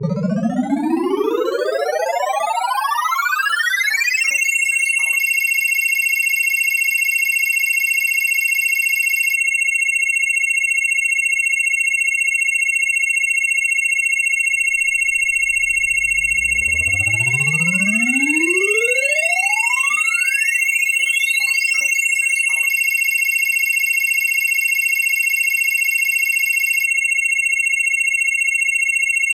SE_RESULT_GAUGE_UP_TOTAL.wav